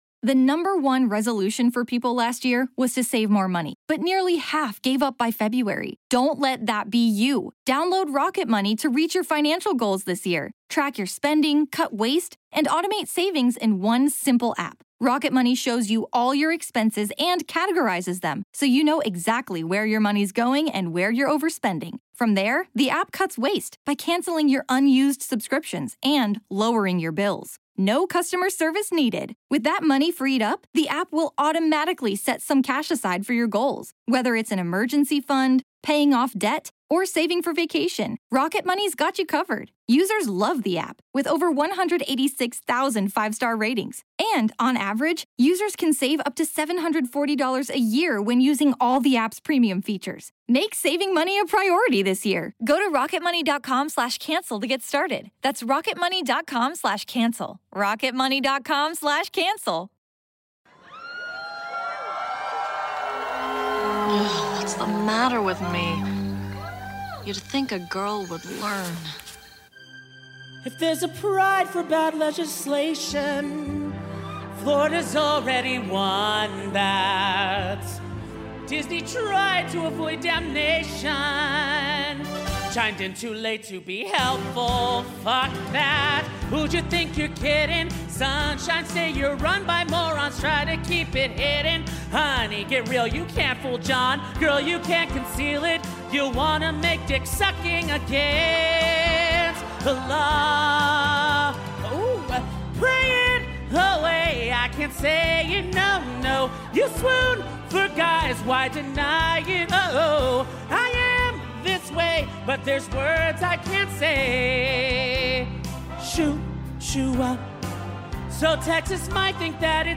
Choo choo! Lovett or Leave It: Live Or Else pulls into the station at Dallas’s own The Factory in Deep Ellum.
Even the confused Lyle Lovett fans in the crowd had a great time.